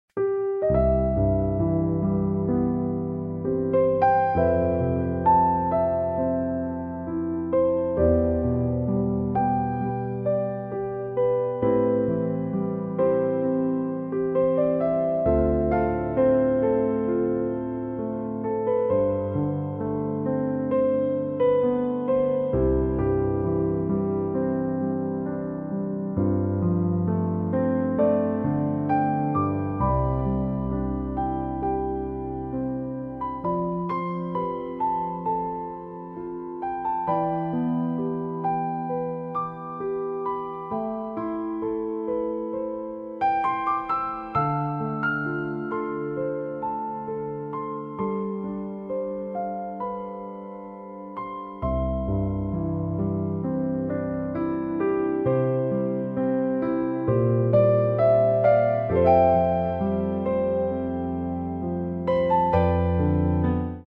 Elegant Piano Music